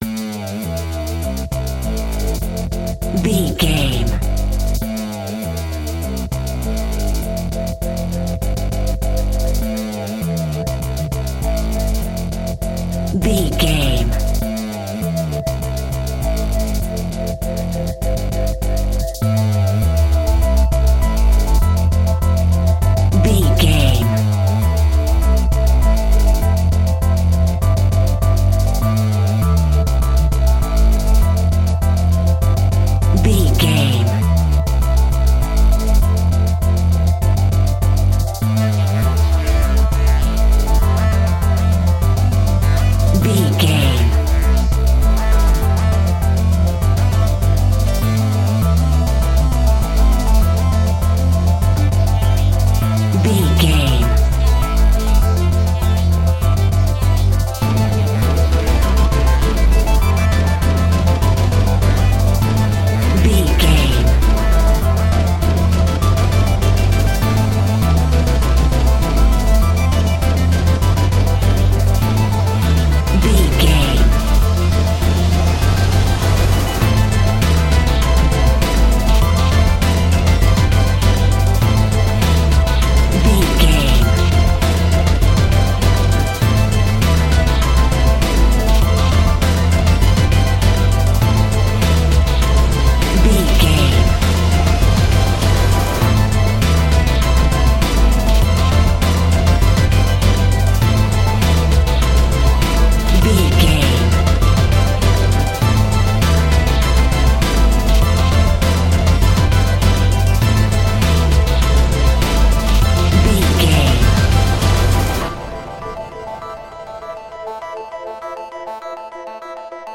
Epic / Action
Fast paced
In-crescendo
Aeolian/Minor
synthesiser
orchestral
orchestral hybrid
dubstep
aggressive
energetic
intense
strings
drums
bass
synth effects
wobbles
driving drum beat